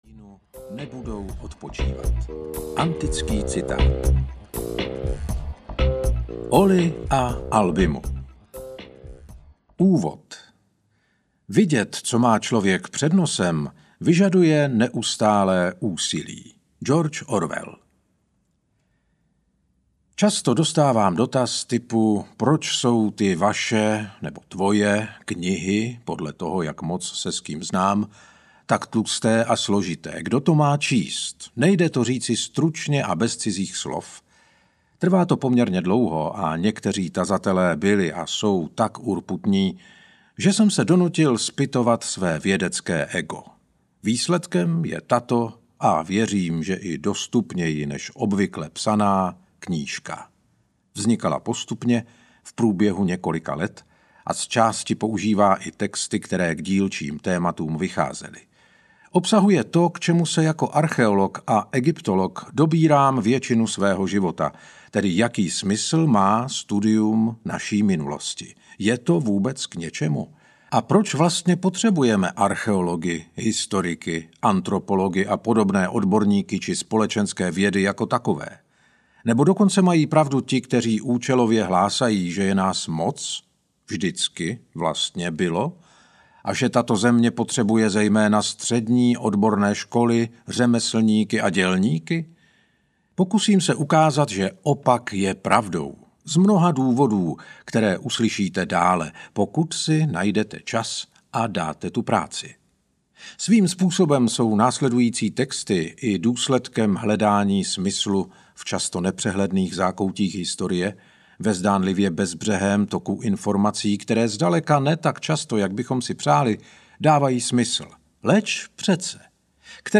Sedm zákonů audiokniha
Ukázka z knihy
sedm-zakonu-audiokniha